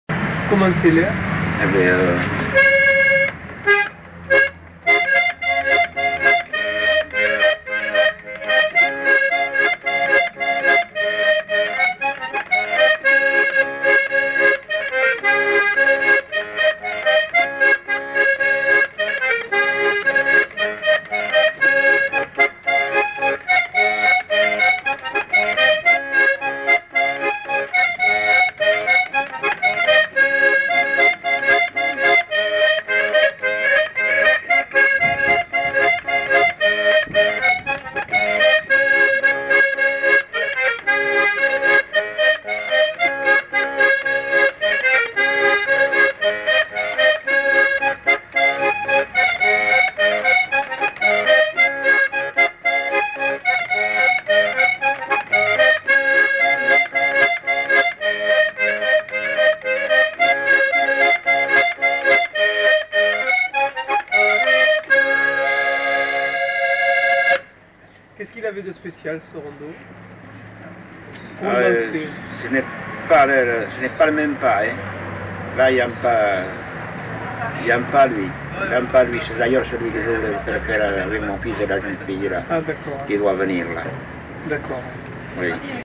Lieu : Mimizan
Genre : morceau instrumental
Instrument de musique : accordéon diatonique
Danse : rondeau